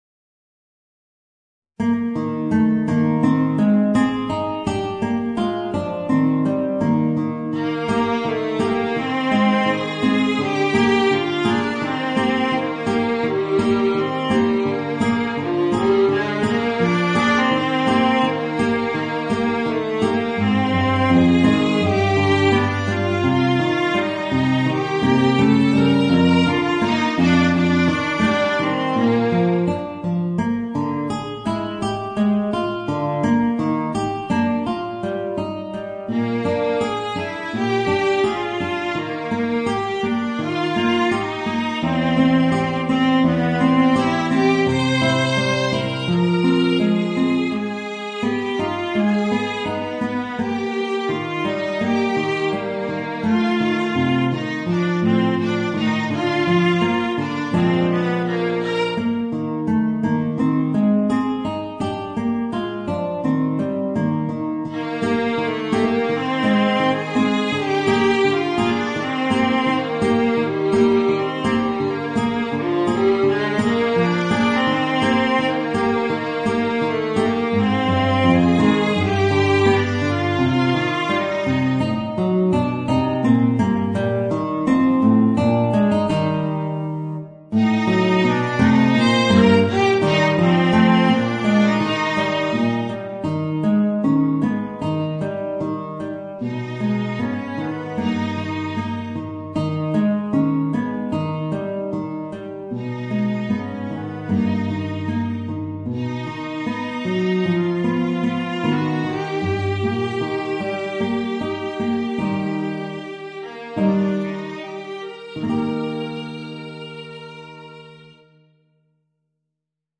Voicing: Viola and Guitar